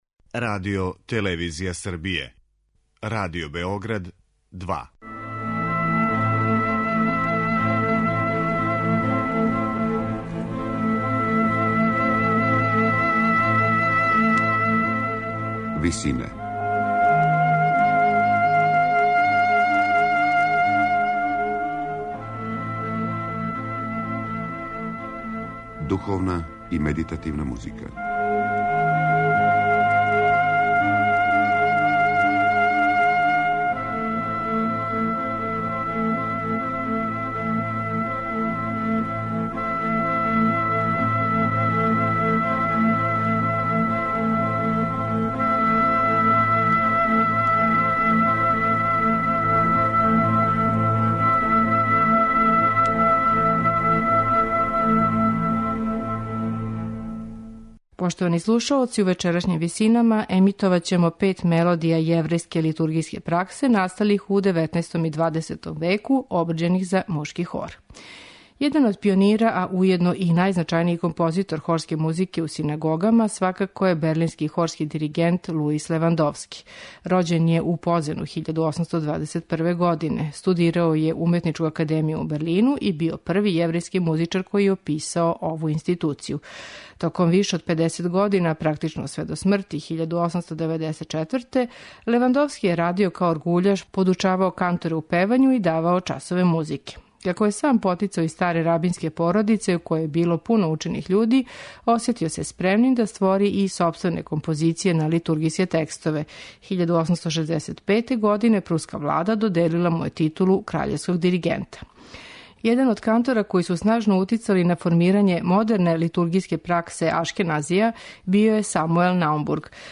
У интерпретацији Лондонског јеврејског мушког хора, чућете литургијску мелодију "Су шорим" Самуела Наумбурга, Вериндеров напев "Есо анаи" и мелодију "Ву рахум" Јозефа Розенблата.